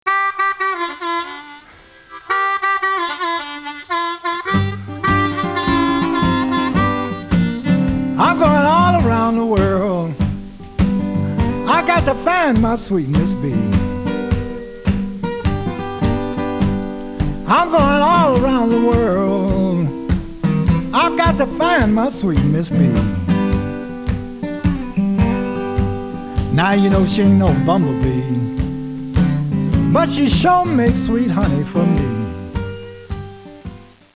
Vocals, harmonica
Acoustic guitar
Electric guitar, mandolin
Acoustic bass
Washboard, percussion, clarinet